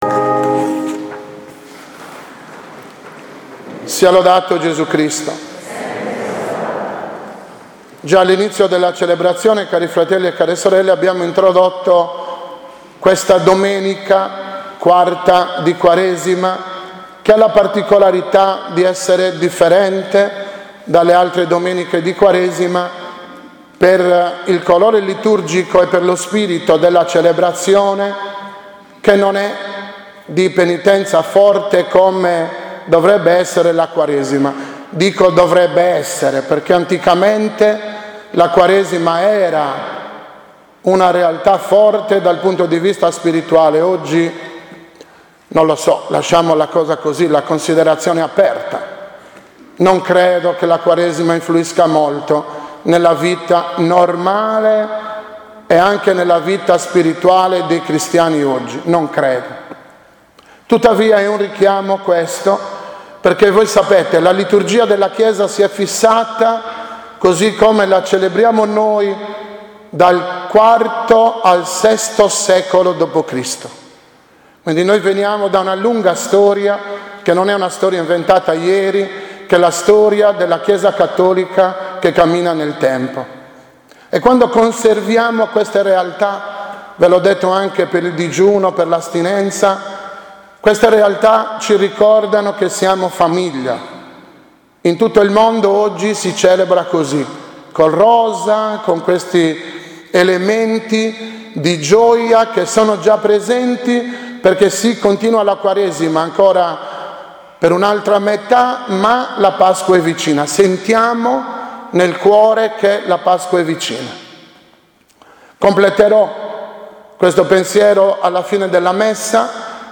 2019 OMELIA DELLA IV DI QUARESIMA C
2019-OMELIA-IV-DI-QUARESIMA-C.mp3